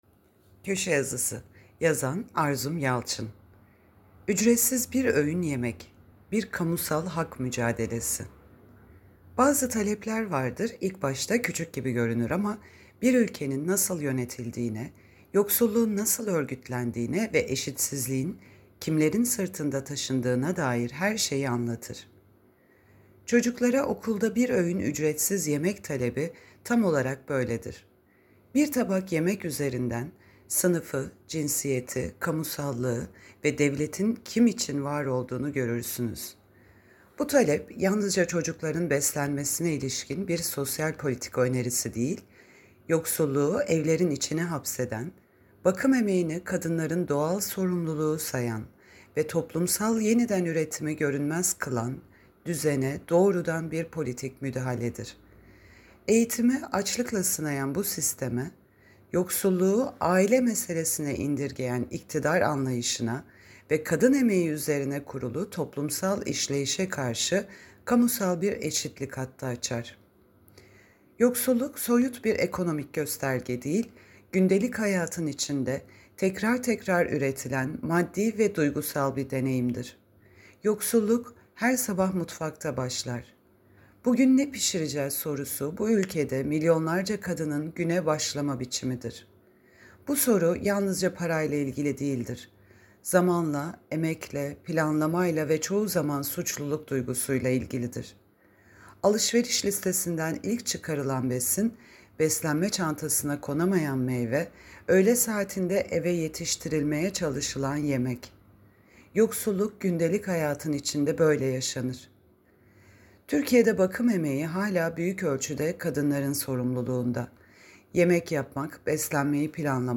Seslendirme